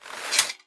holster1.ogg